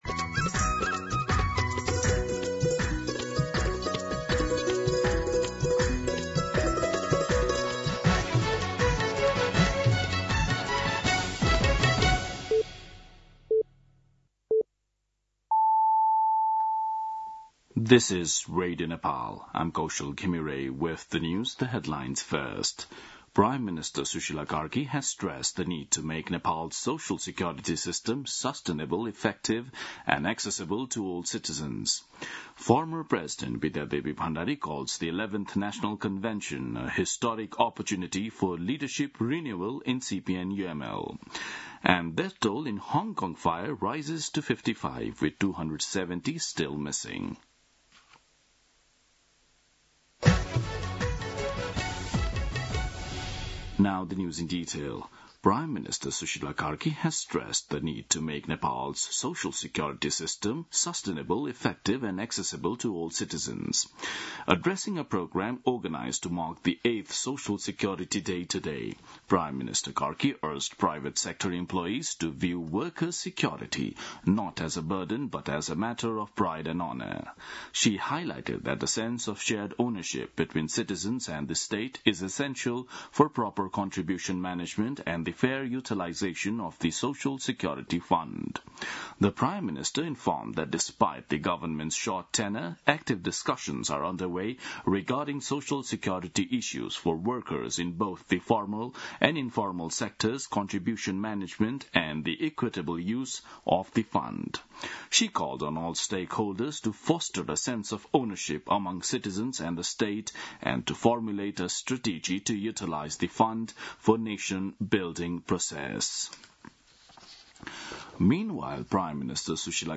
दिउँसो २ बजेको अङ्ग्रेजी समाचार : ११ मंसिर , २०८२
2-pm-English-news-8-11.mp3